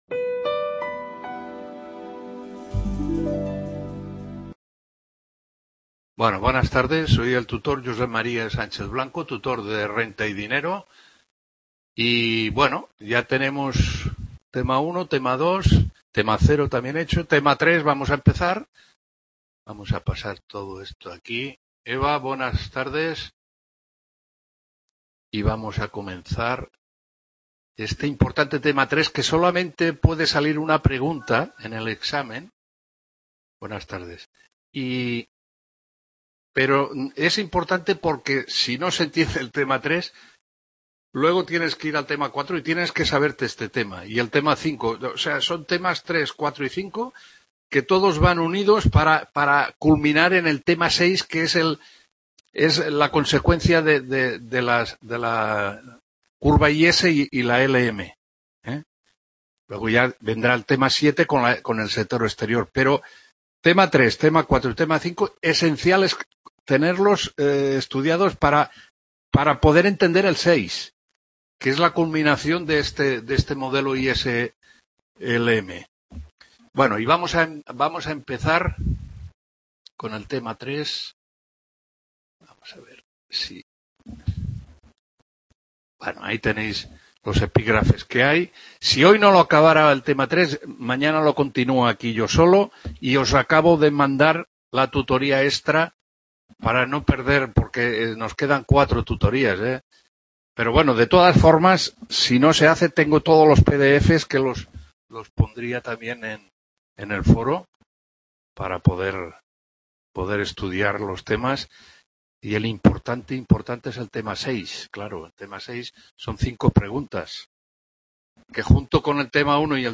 7ª TUTORÍA (1ª PARTE) CURVA IS MERCADO BIENES- RENTA Y… | Repositorio Digital